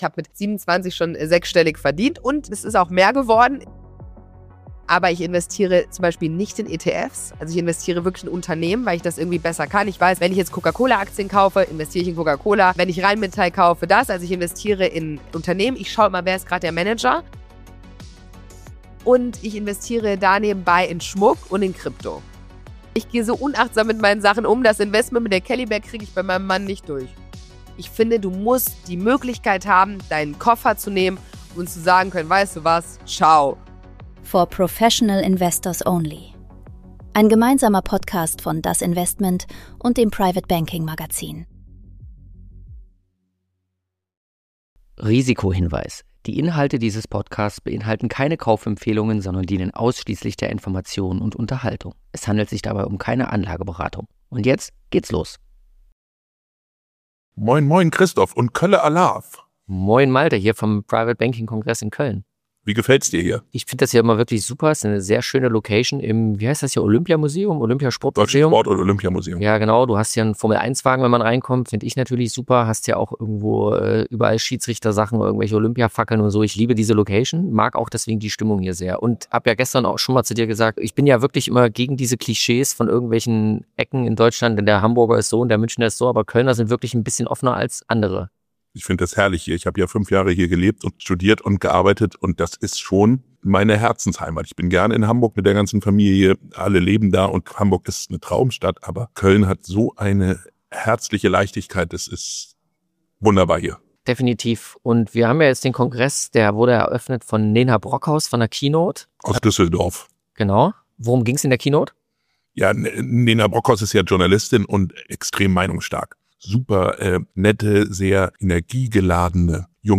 Beim private banking kongress in Köln spricht sie